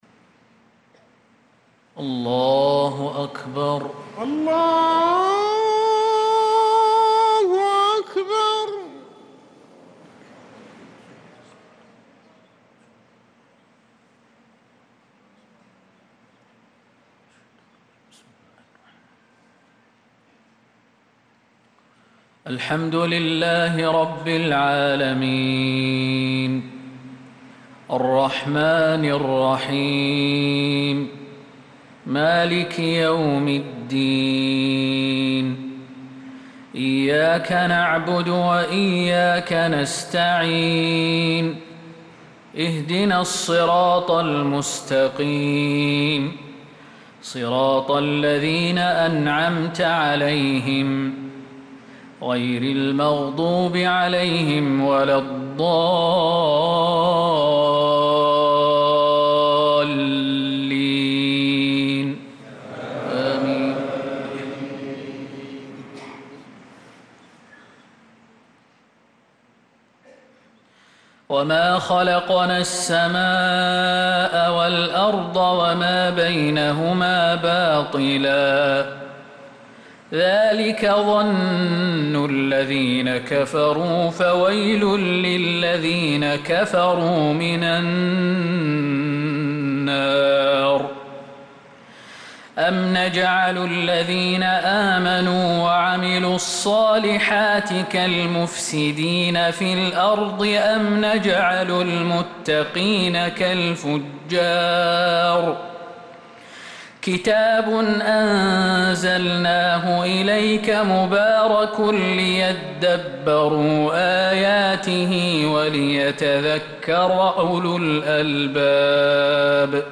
صلاة العشاء للقارئ خالد المهنا 27 ذو القعدة 1445 هـ
تِلَاوَات الْحَرَمَيْن .